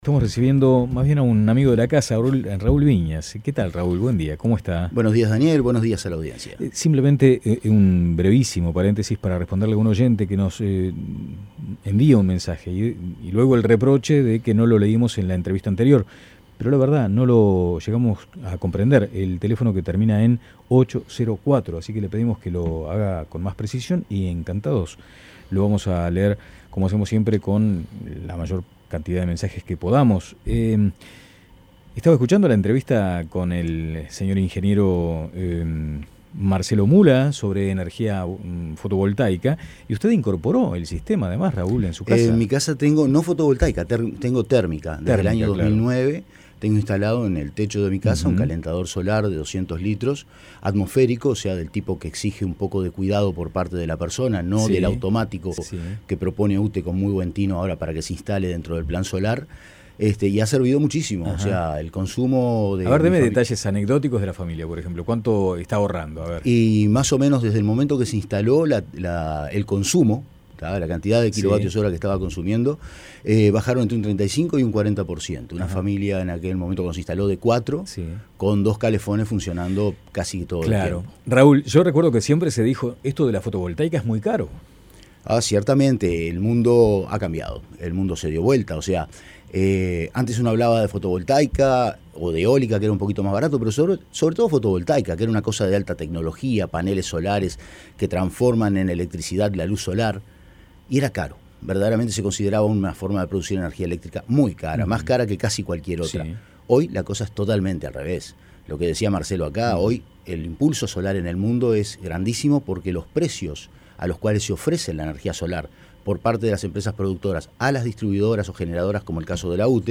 Escuche la entrevista en La Mañana